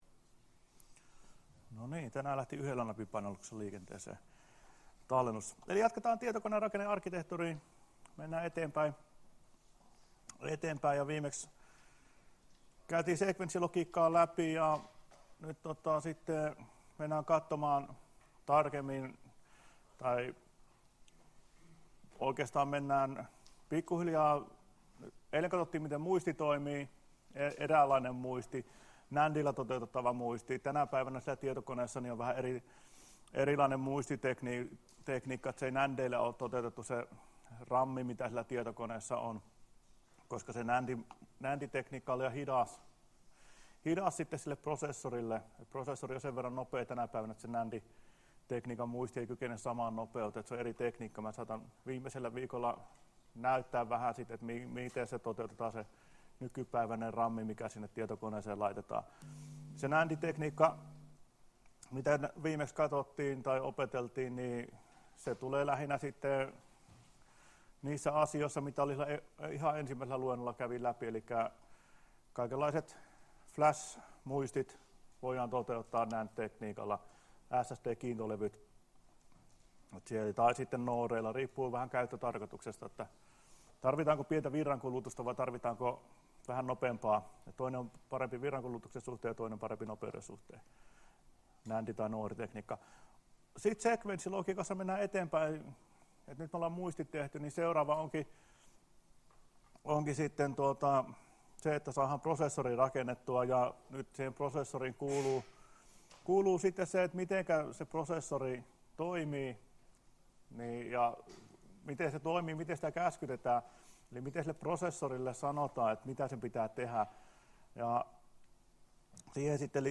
Luento 5.10.2016 — Moniviestin